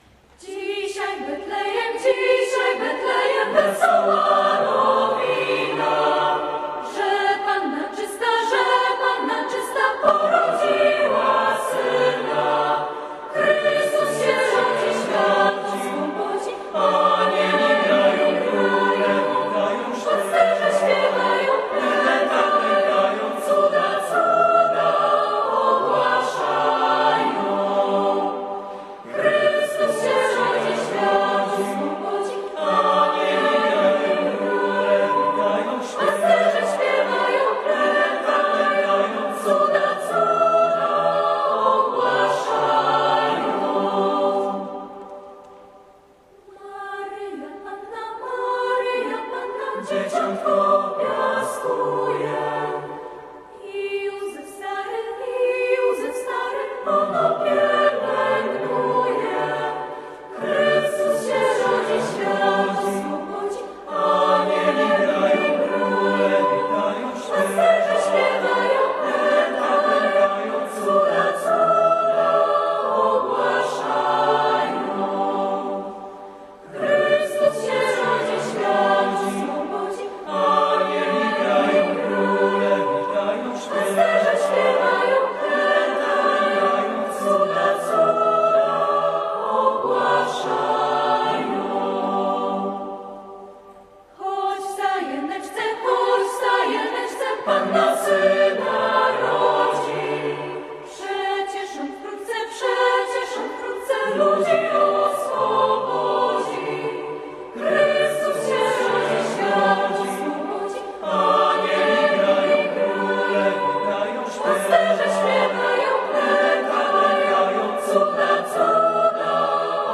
Kantylena – Lubelski Festiwal Chórów Parafialnych
Chór Kantylena III Liceum Ogólnokształcącego im. Unii Lubelskiej w Lublinie